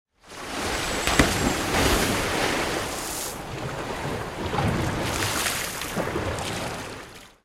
Звуки косатки
Брызги косаткой